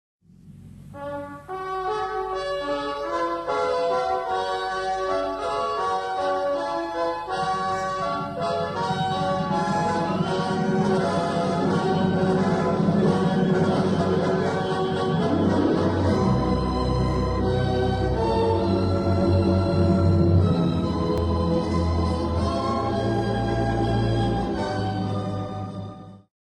Konzertstück für Orchester